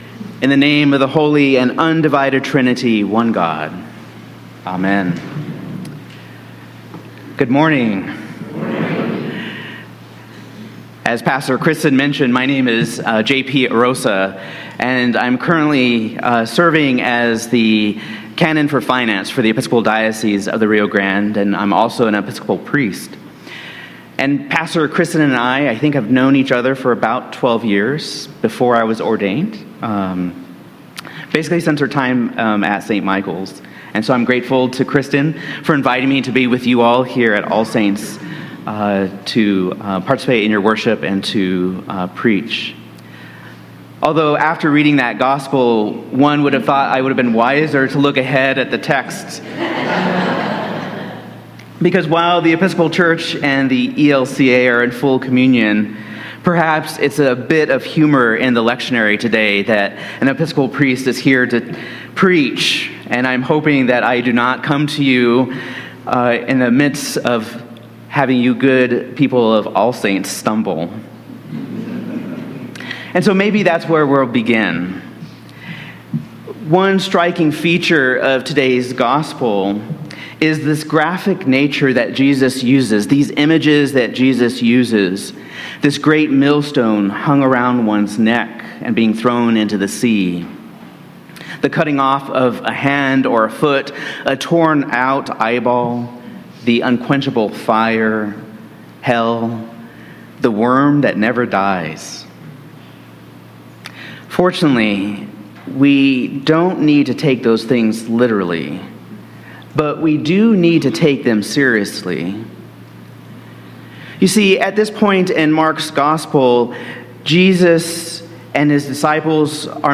1 Kings 8:1-13 Service Type: Sunday Morning